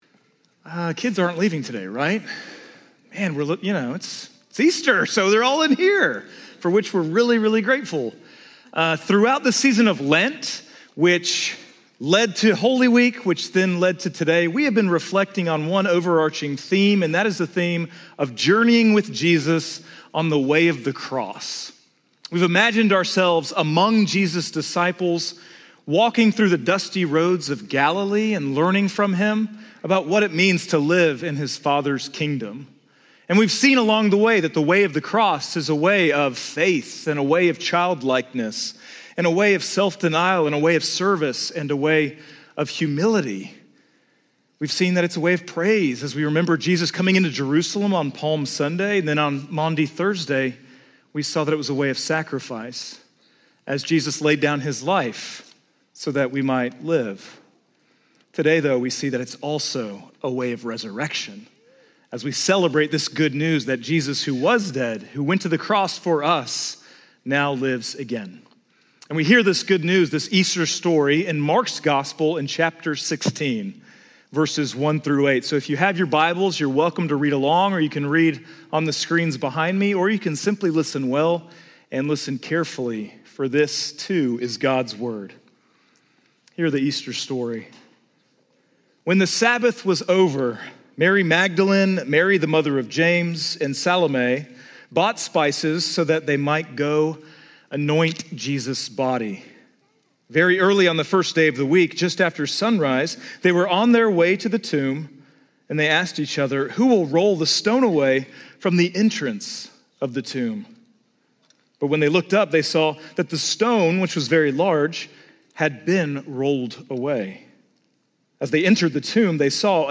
Sermon-Audio-4.5.26.mp3